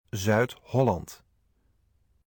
South Holland (Dutch: Zuid-Holland [ˌzœyt ˈɦɔlɑnt]